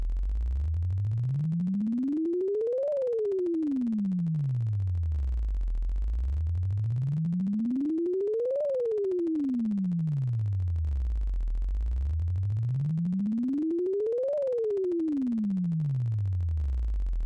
GenerateSweepWaveFile